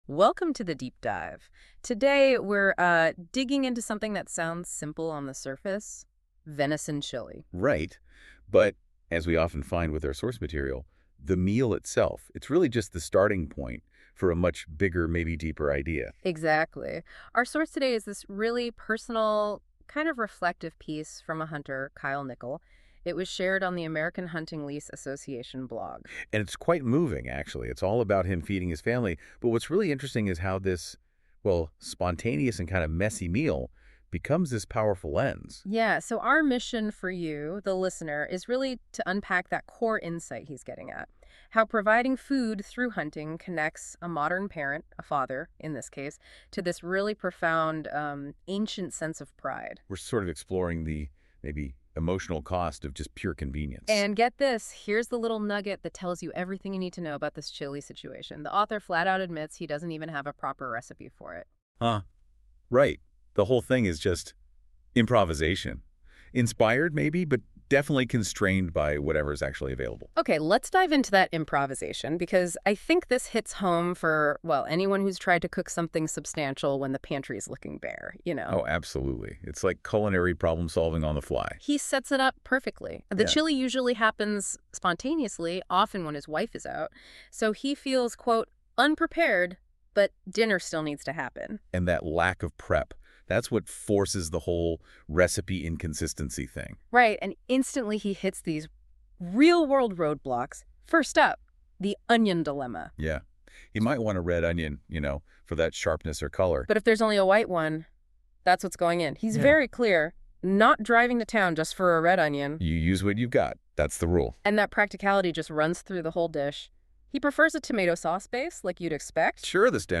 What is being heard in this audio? Audio summary: